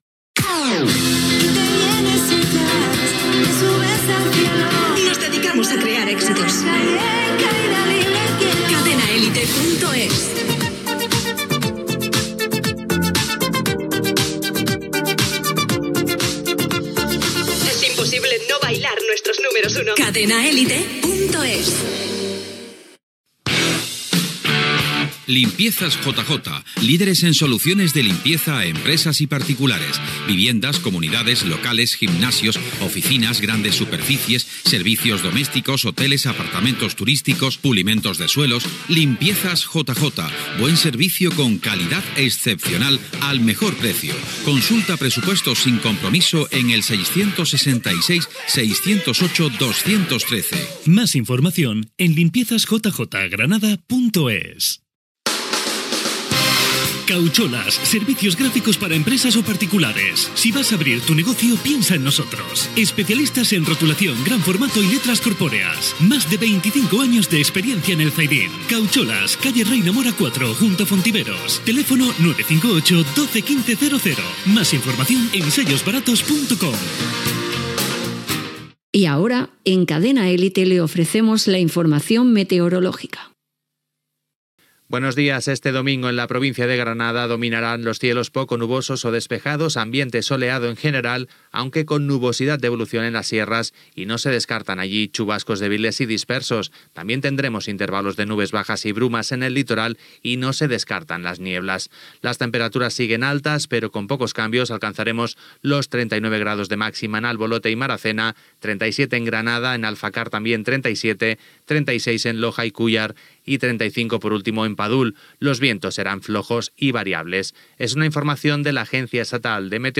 Musical
Indicatiu, publicitat, informació meterorològica de Granada, promoció "Lo mejor de la semana", tema musical